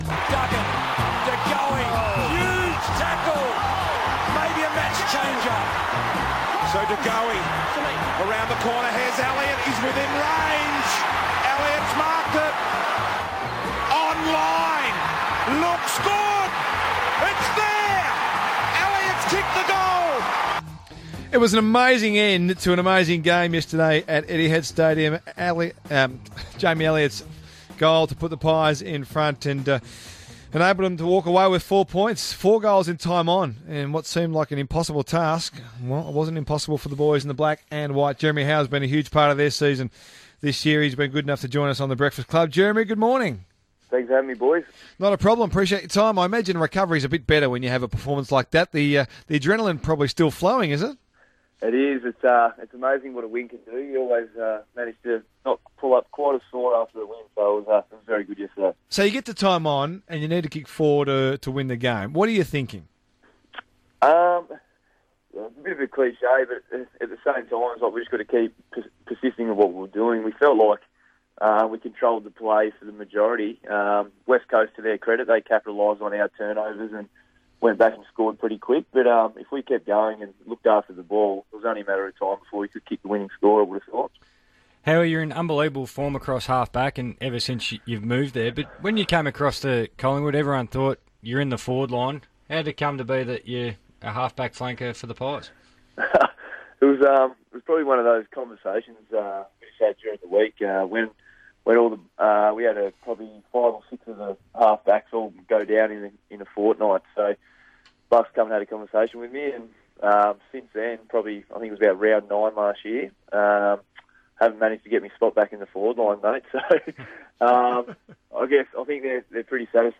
Radio: Jeremy Howe on RSN